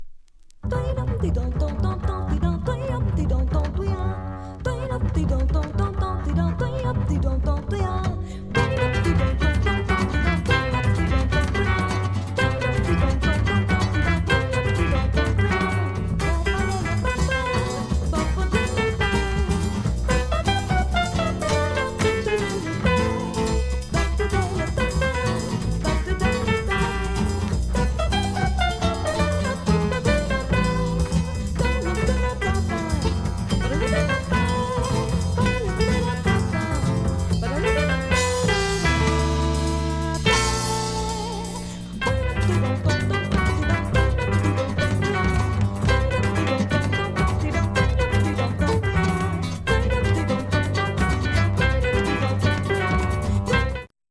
大推薦レア・ドイツ産ブラジリアン・アルバム！！！